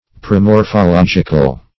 Search Result for " promorphological" : The Collaborative International Dictionary of English v.0.48: Promorphological \Pro*mor`pho*log"ic*al\, a. (Biol.) Relating to promorphology; as, a promorphological conception.
promorphological.mp3